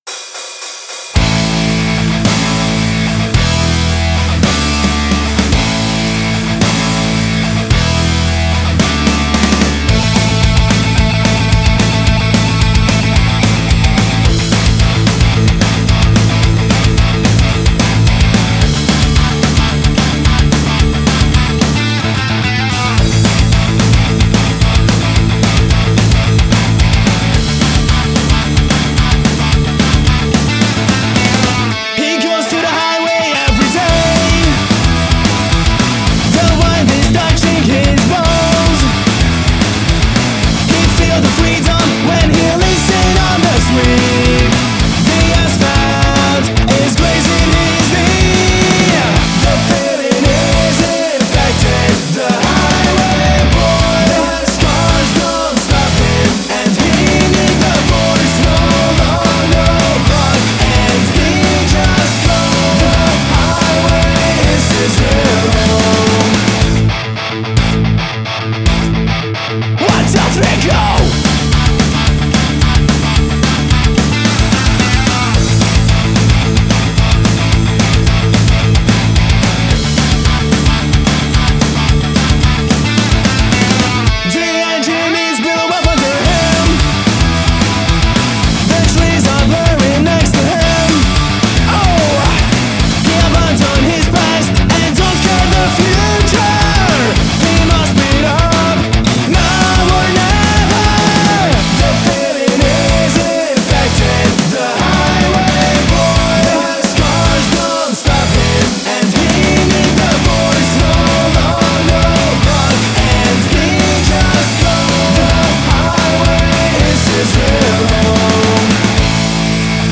bass/vocals
vocals/guitars